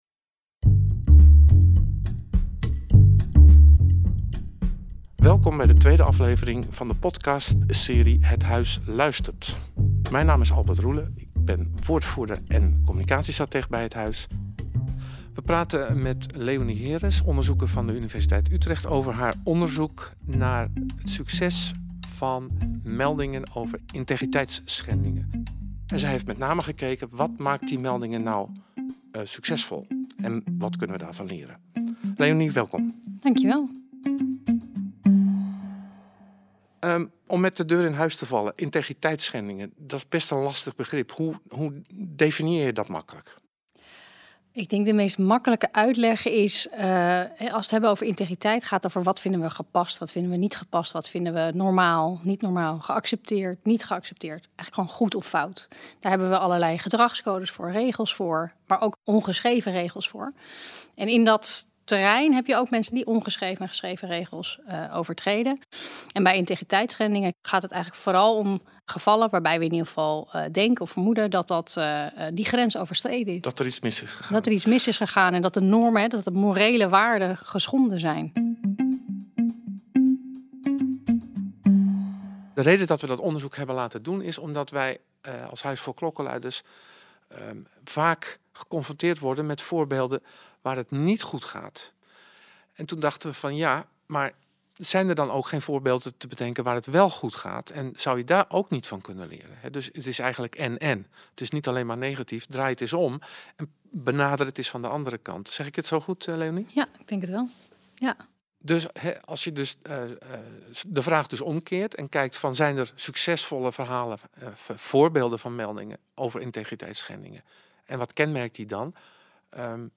vraaggesprek